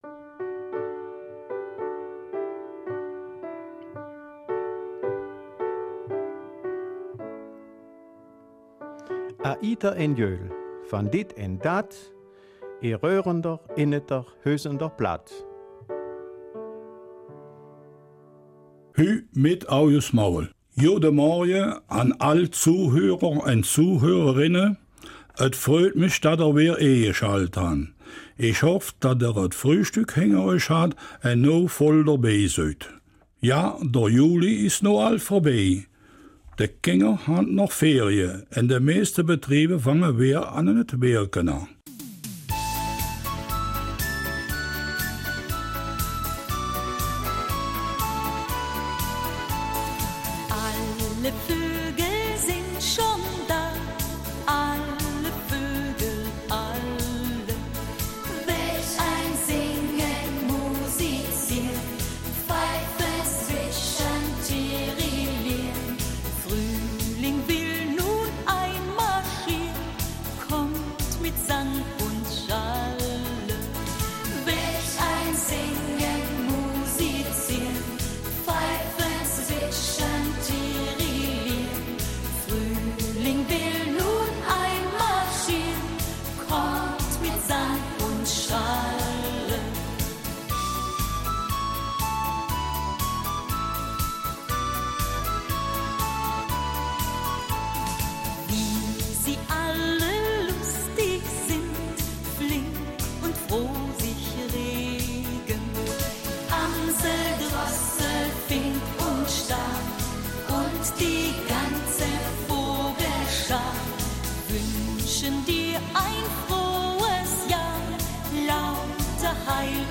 Raerener Mundart am 31. Juli